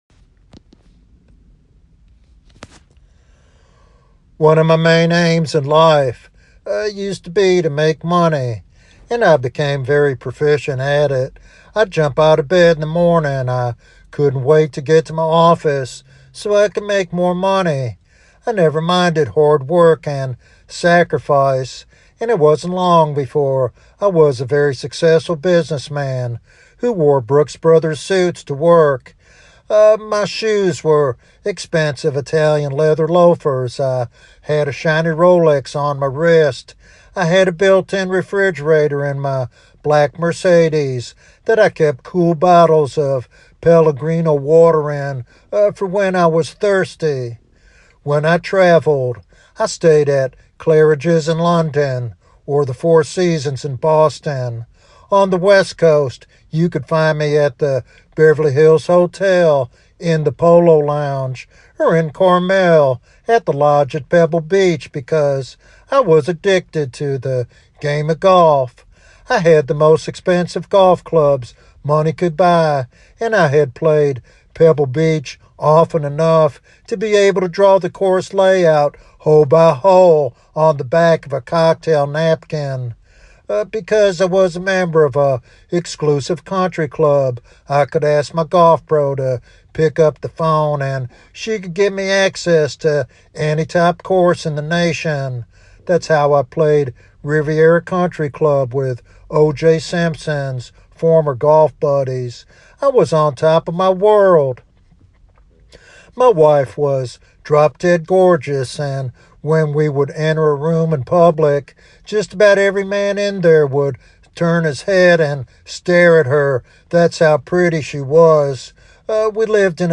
This sermon offers hope and a clear call to repentance for all seeking true purpose.